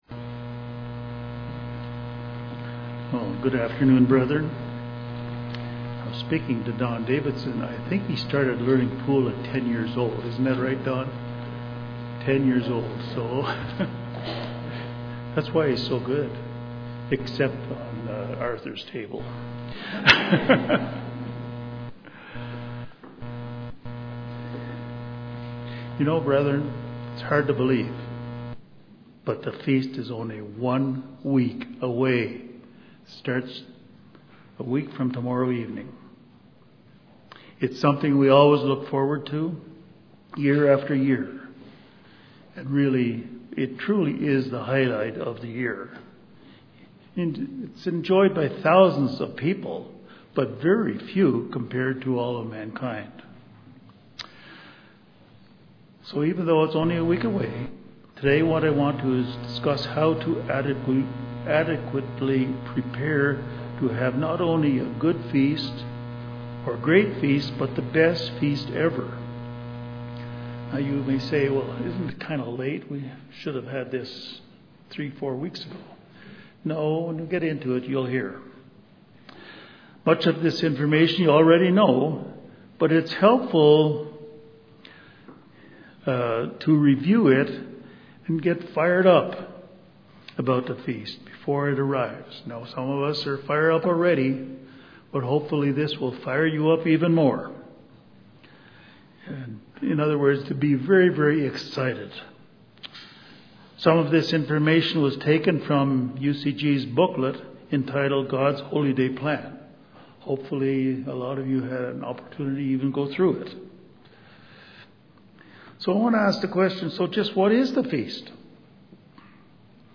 This sermon discusses how to adequately prepare for the Feast. It includes proper physical, mental and spiritual preparation so this year’s feast truly will be the best ever.